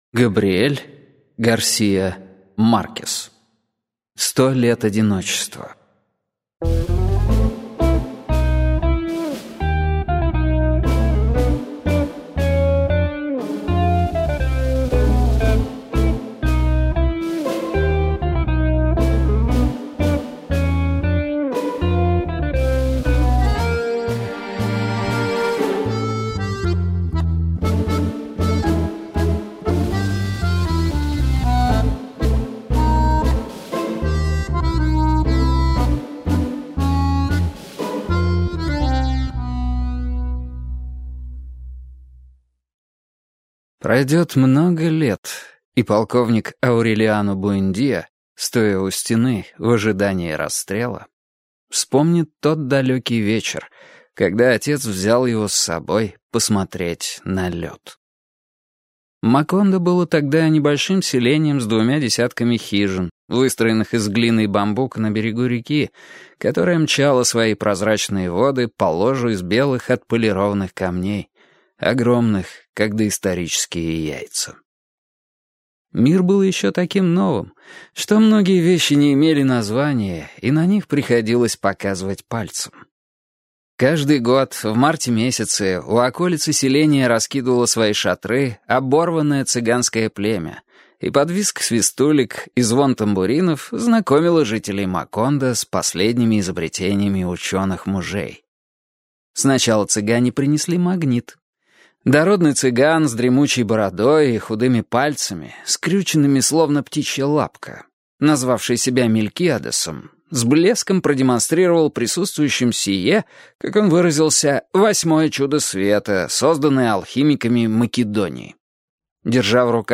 Аудиокнига Сто лет одиночества | Библиотека аудиокниг